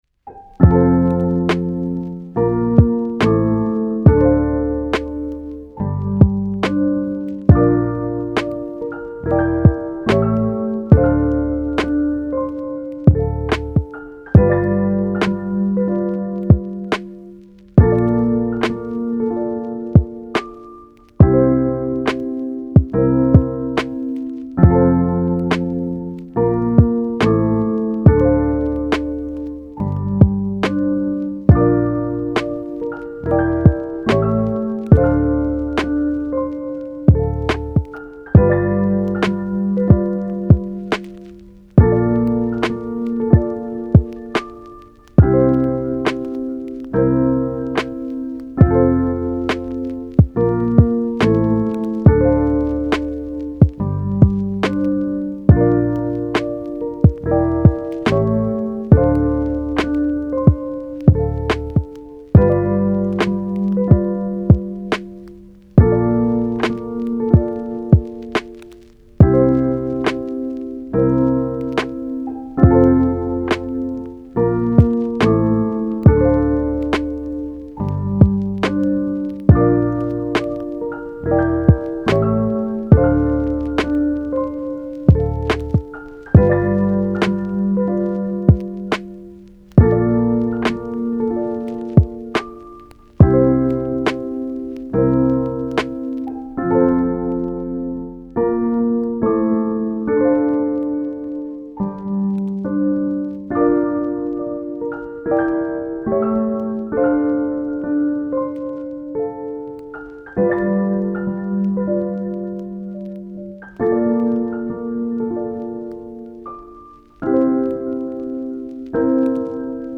カフェミュージック チル・穏やか フリーBGM